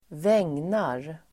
Uttal: [²v'eng:nar]